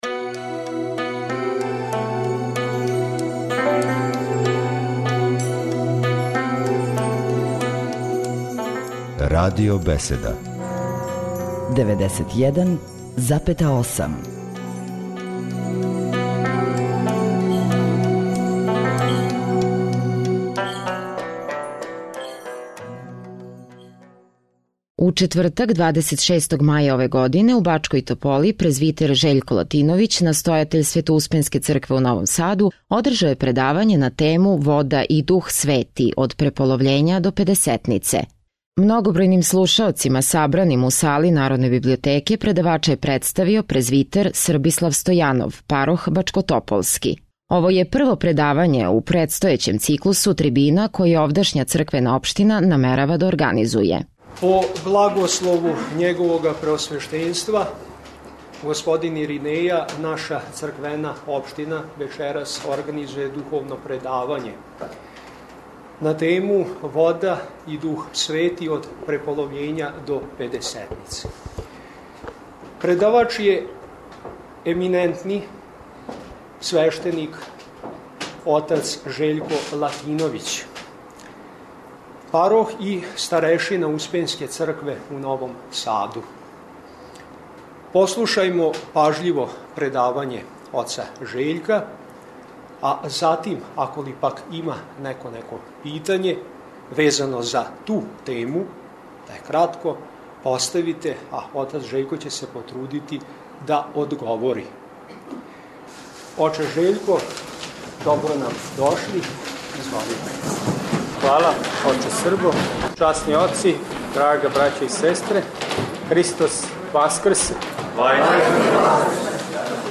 Многобројним слушаоцима сабраним у сали Народне библиотеке
Ово је прво предавање у предстојећем циклусу трибина које овдашња Црквена општина намерава да организује.